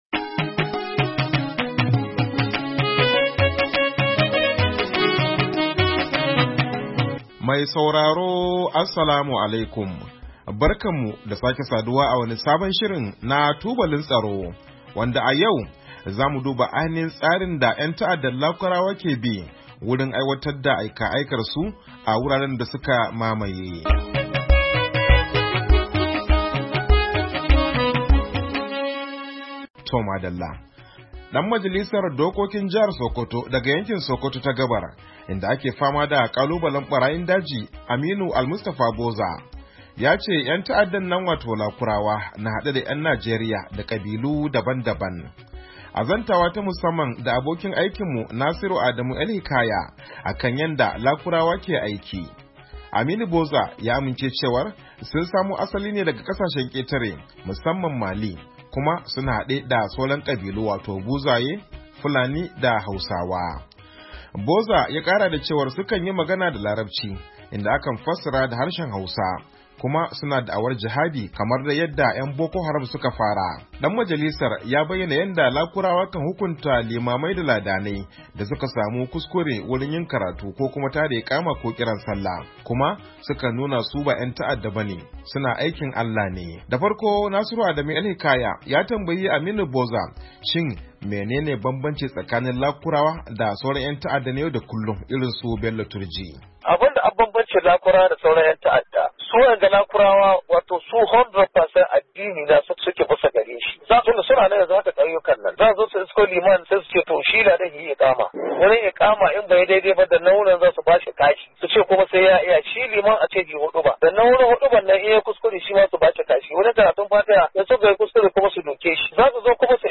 TUBALIN TSARO: Hira Da Dan Majalisa Akan Barayin Daji, ‘Yan Ta’adda A Jihar Sakkwato, Nuwamba 29, 2024
A shirin Tubalin Tsaro na wannan makon mun tattauna ne da dan majalisar dokokin jihar Sakkwato, Aminu Almustapha Boza akan fama da kalubalen barayin daji da ‘yan ta’addan nan Lakurawa, da ya amince cewa sun samo asali ne daga kasashen ketare musamman Mali kuma su na hade da Buzaye, Fulani da...